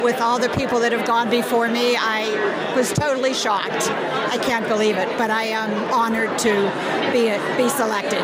After the chamber meeting, she said she was honored to be named to the hall of fame.